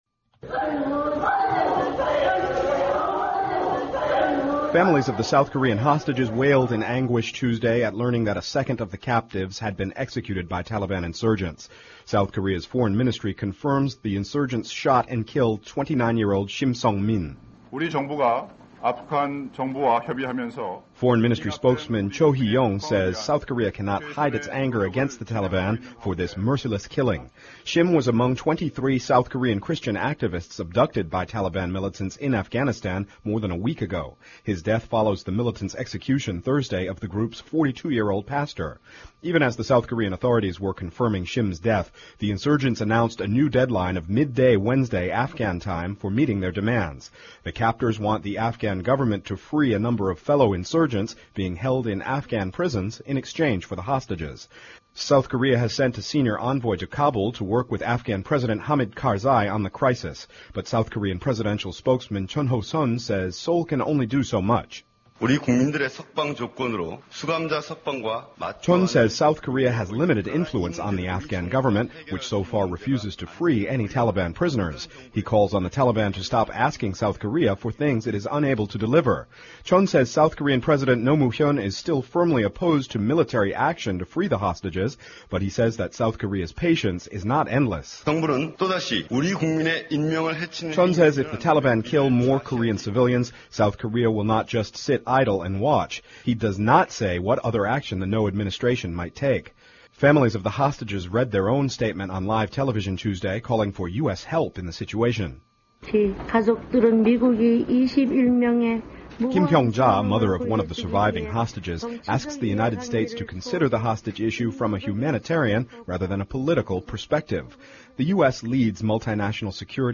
您现在的位置是：首页 > 英语听力 > VOA英语听力下载|VOA news > voa标准英语|美国之音常速英语下载|在线收听